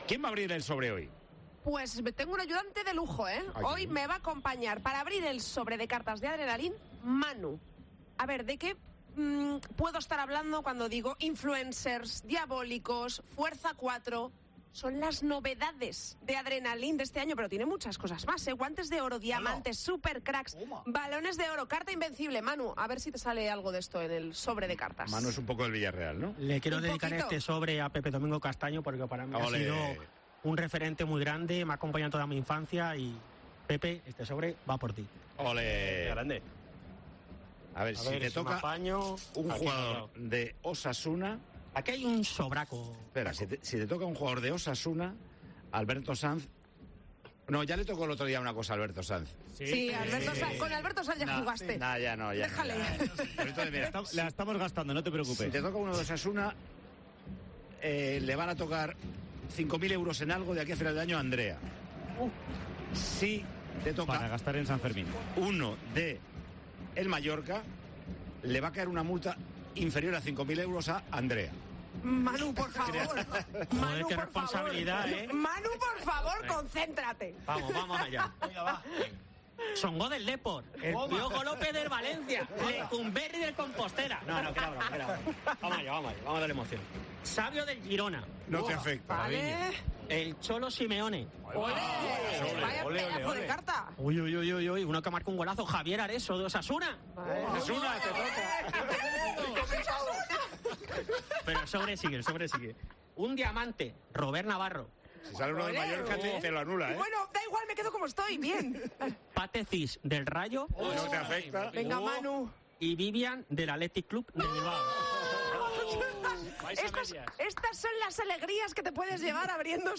Gritos y alegría en el estudio.